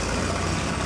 RATTLE.mp3